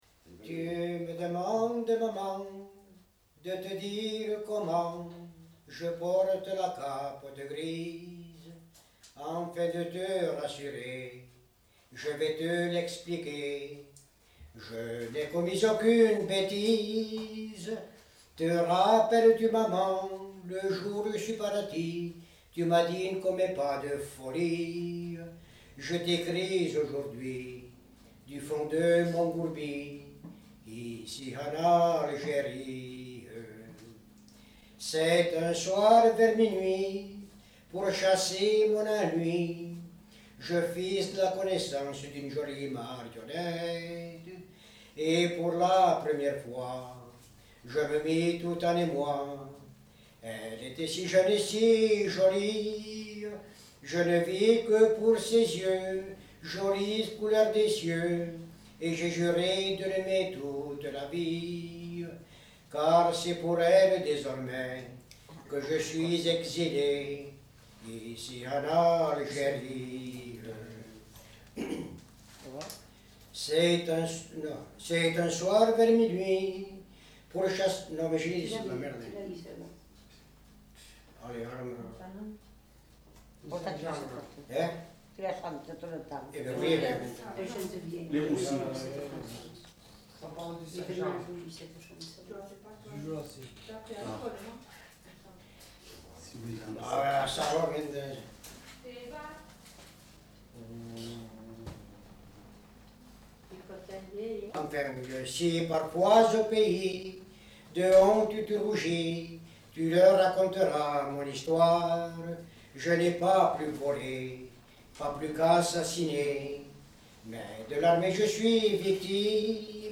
Aire culturelle : Lauragais
Lieu : Revel
Genre : chant
Effectif : 1
Type de voix : voix d'homme
Production du son : chanté